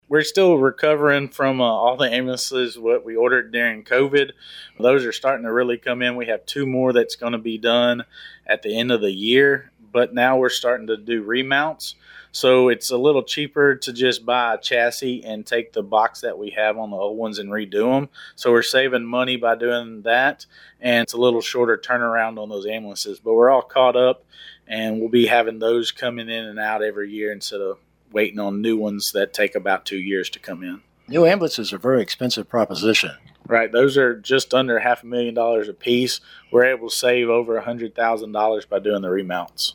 Our guest on our “Around Town” public affairs program over the weekend was Walker County Judge Colt Christian.